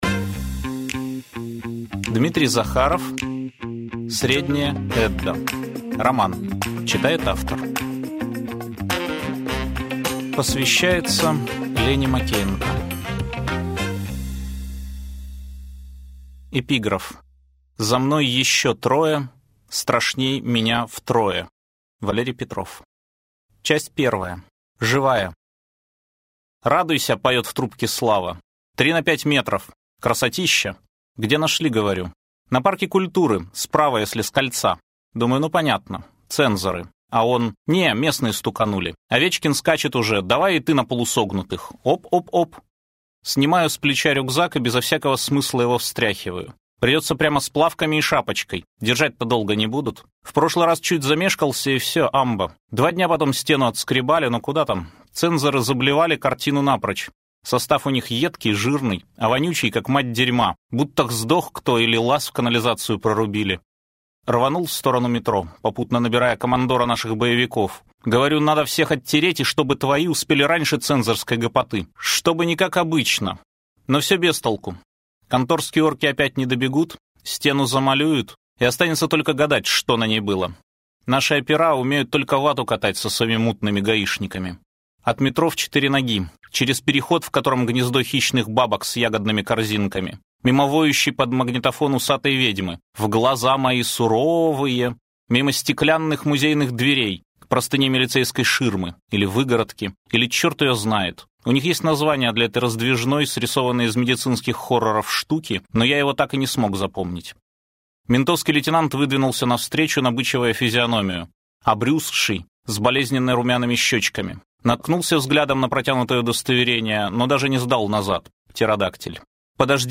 Аудиокнига Средняя Эдда | Библиотека аудиокниг